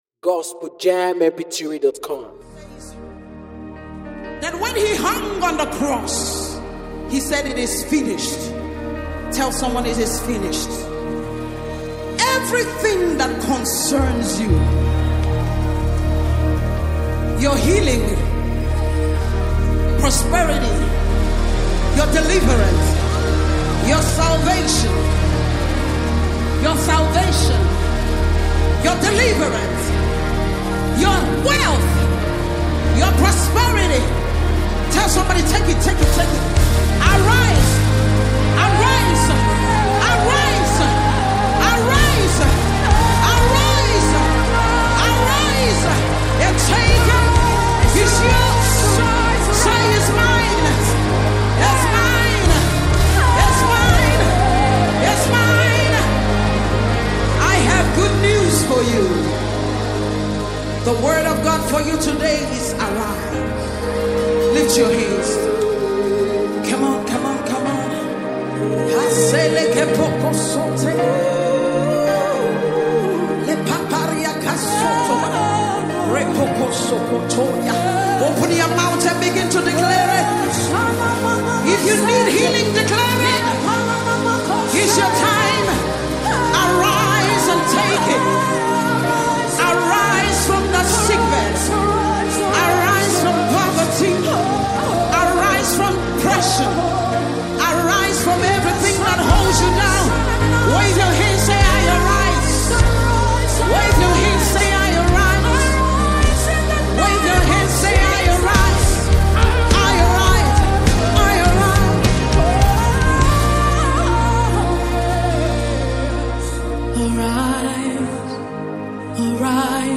a powerful prophetic worship song